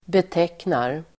Uttal: [bet'ek:nar]